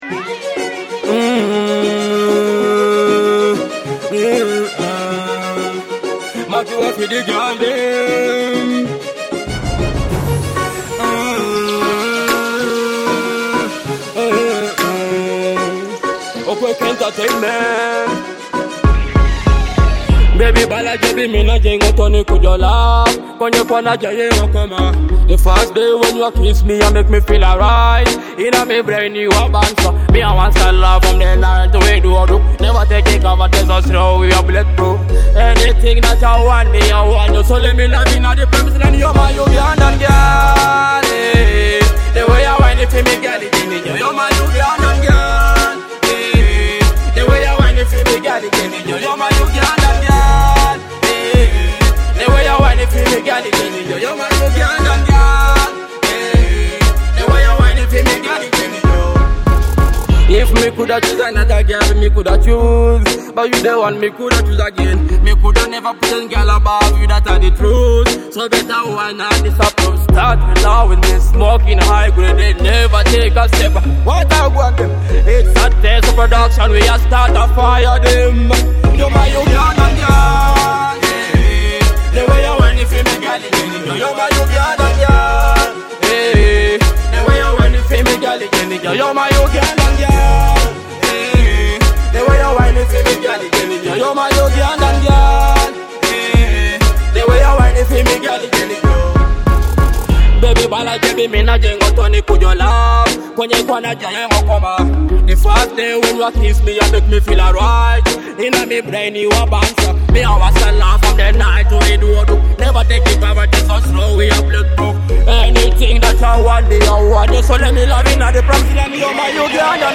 Afrobeat and Dancehall
With a vibrant Teso-inspired beat and feel-good lyrics